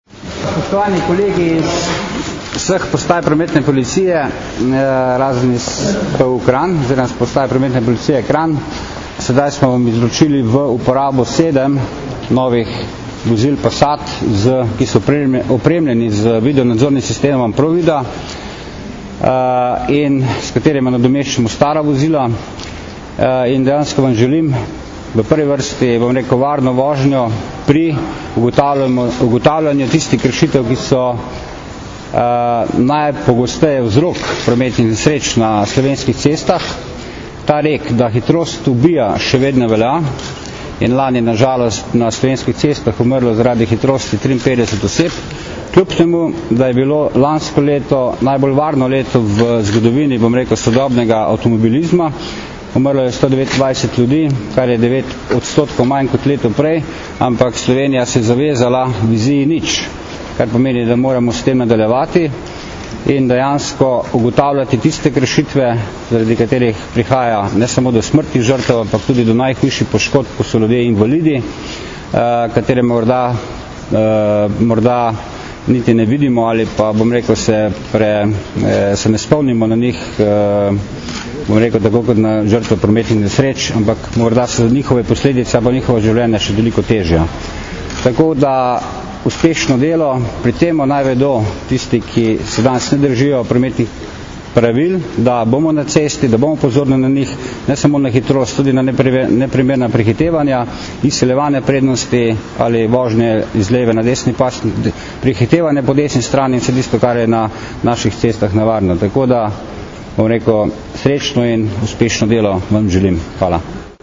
Zvočni posnetek izjave generalnega direktorja policije (mp3)